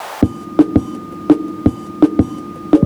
FXBEAT05-L.wav